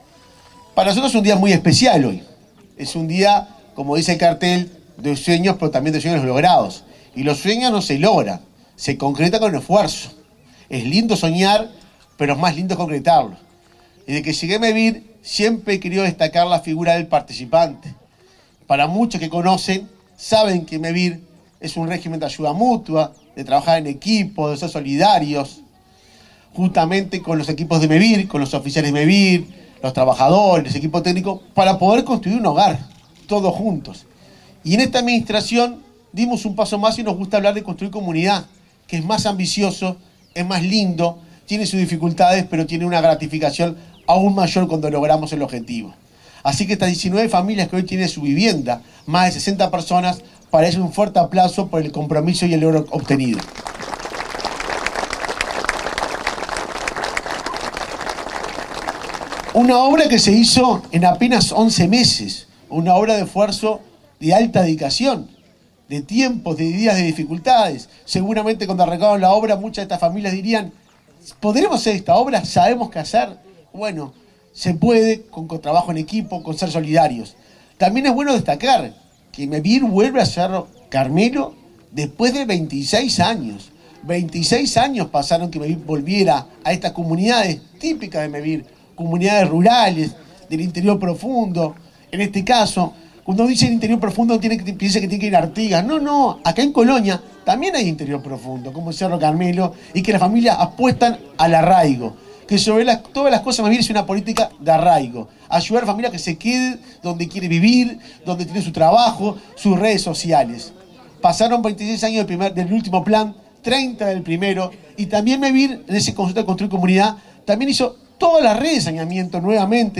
Juan-Pablo-Delgado-Presidente-de-MEVIR.mp3